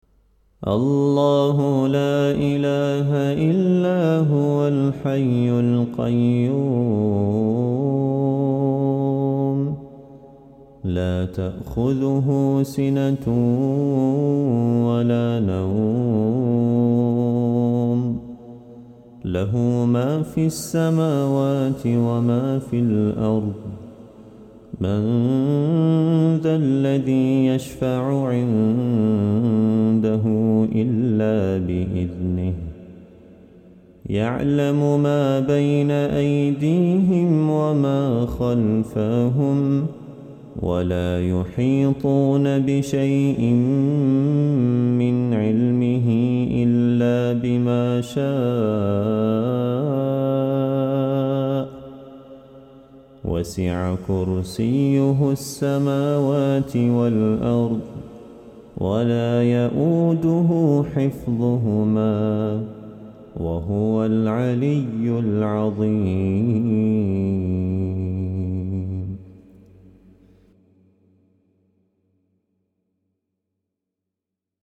Recitime